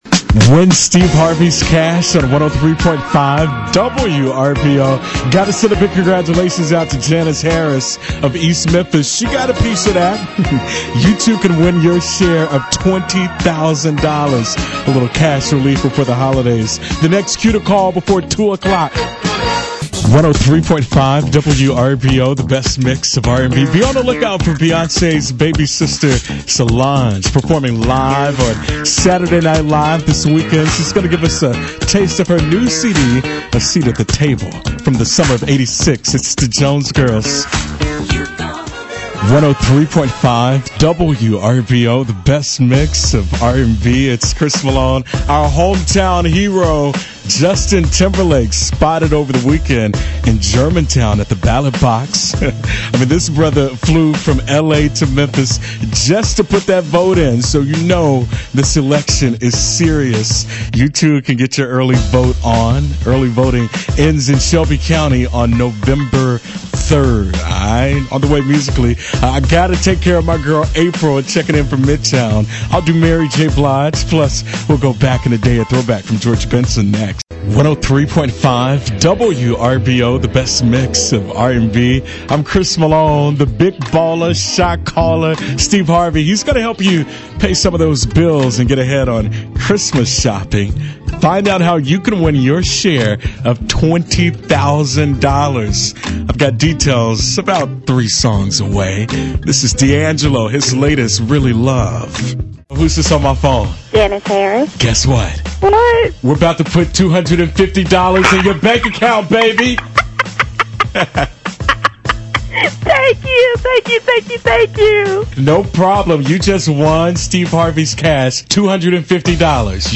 On-Air Personality:  103.5 WRBO (Urban AC):